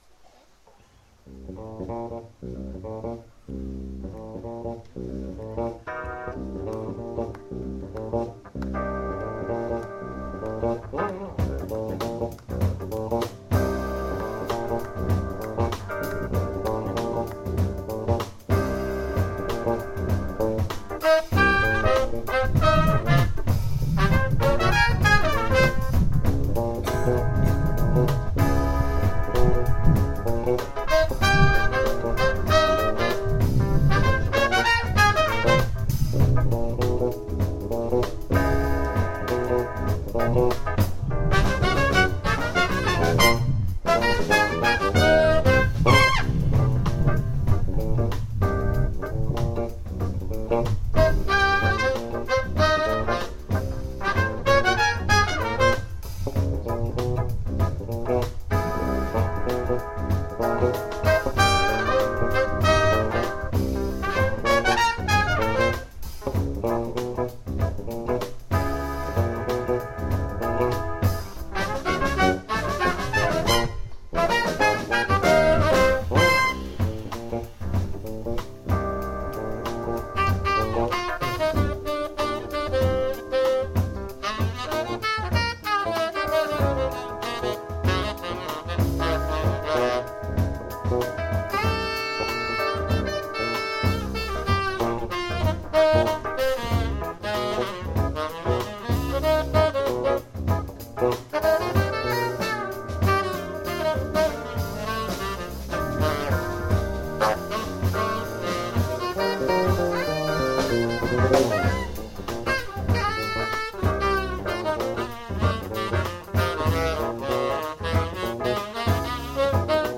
Jedes Jahr im August geben sich Musiker des Vereins auf der herrlichen Freilichtbühne des Fürther Stadtparks ein Stelldichein.
Die Tonqualität ist sehr einfach und der Wind weht manchmal,
saxophon
trumpet
mallets
keyboards
e-bass
drums